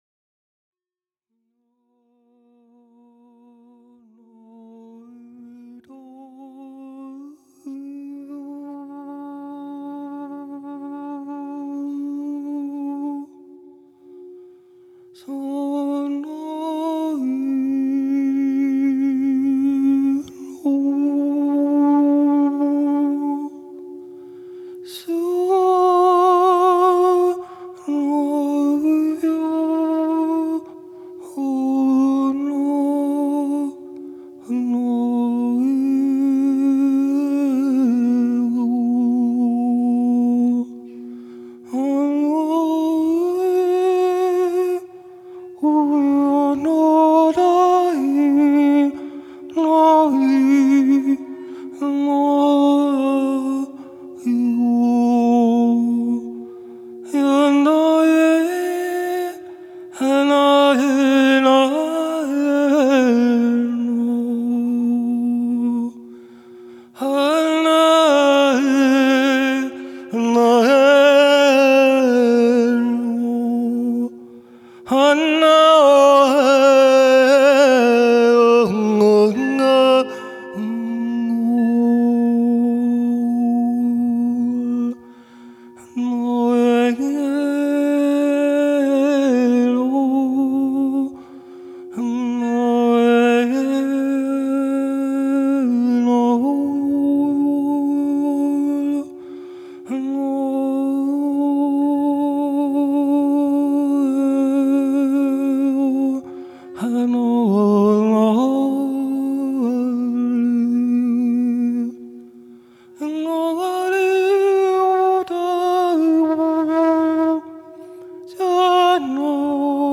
TK-Ib-Portal-Sound-Med-Extended.mp3